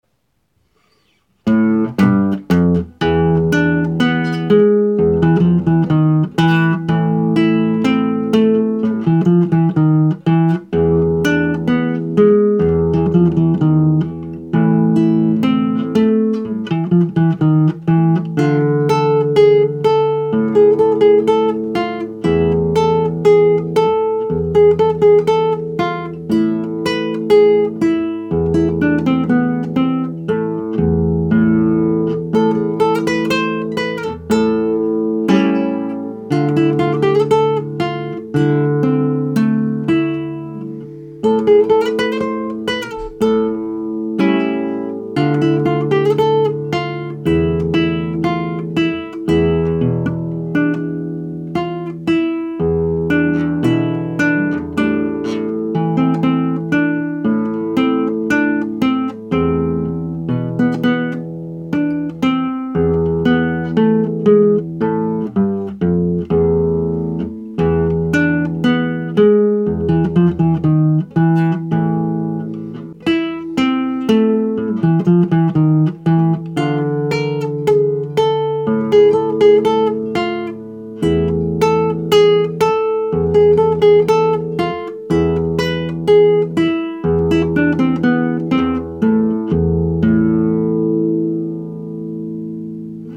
Кумпарсіта Iнші твори складності "medium" Andantino Кубінский танець > Кумпарсіта Кумпарсіта - це запальне Аргентиньске танго, під час виконання якого тіло рветься в танець.